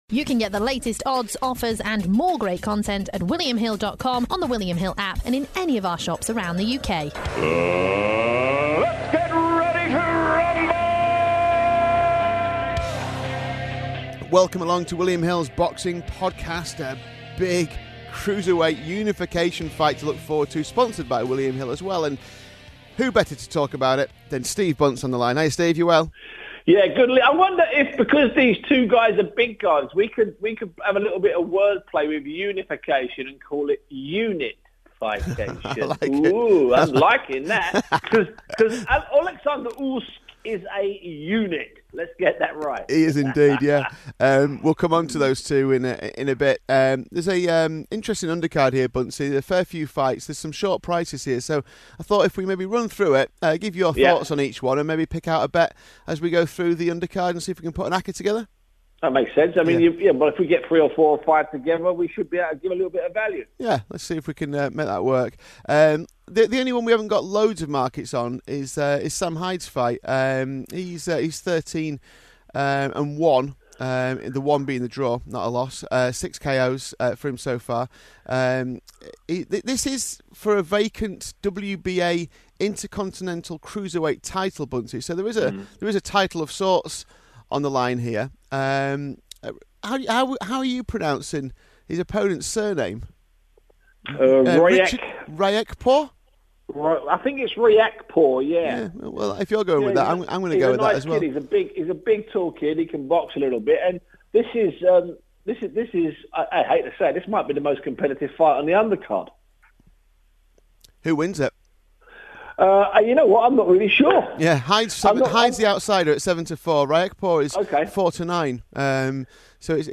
We also hear the thoughts of Tony Bellew on his chances of making history in becoming the first Briton to hold four world titles in a weight division.